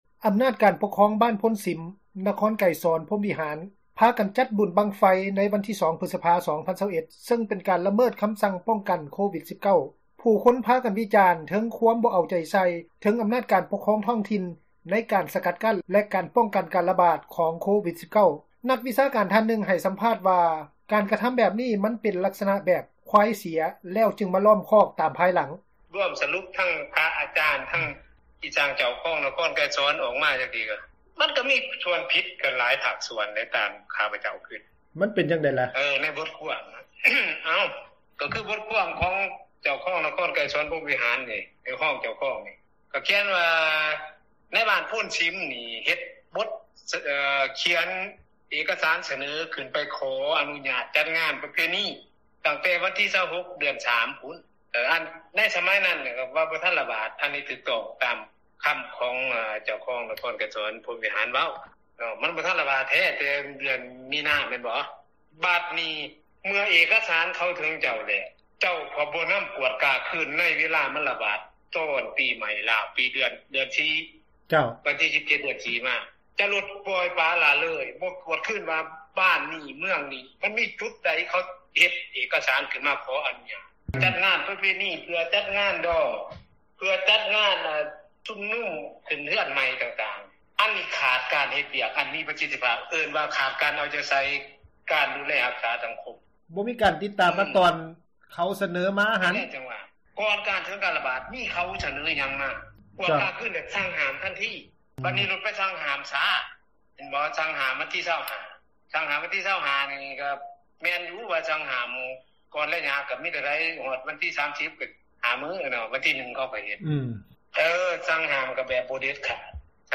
ນັກວິຊາການທ່ານນຶ່ງ ໃຫ້ສຳພາດວ່າ: ການກະທຳແບບນີ້ ເປັນລັກສນະເພິ່ນວ່າ ຄວາຍເສັຽຈັ່ງລ້ອມຄອກຕາມພາຍຫລັງ.